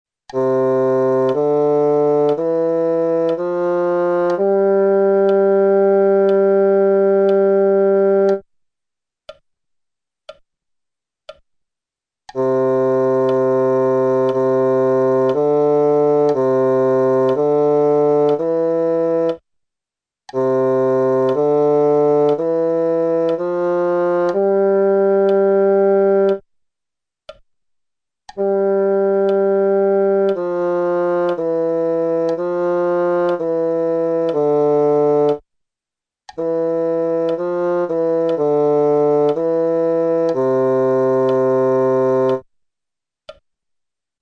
Prima dei dettati veri e propri, sentirai le note che verranno proposte, seguite da una battuta vuota scandita nella divisione dal metronomo.
Note: Do - Re - Mi - Fa - Sol
Tempo: 4/4